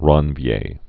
(ränvyā, rän-vyā, räɴ-)